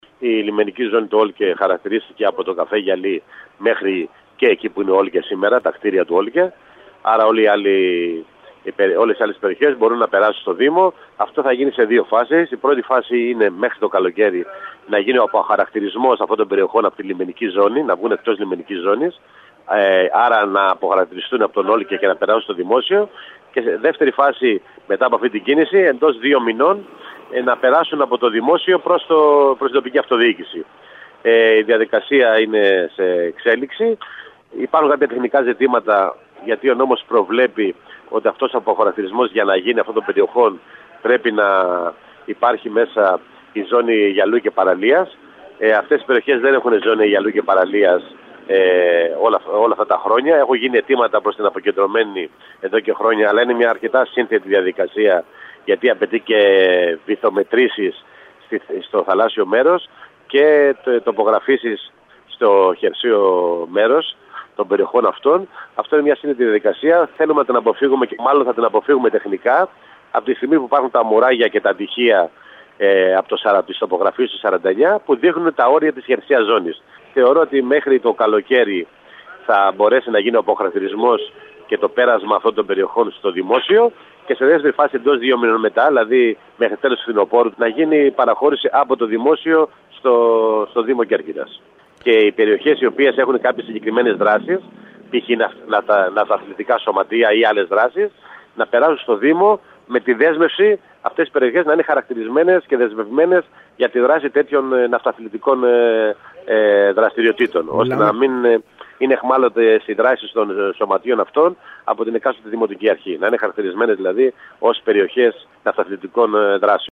Ακούστε απόσπασμα των δηλώσεων του Κ. Παυλίδη στο σύνδεσμο που ακολουθεί: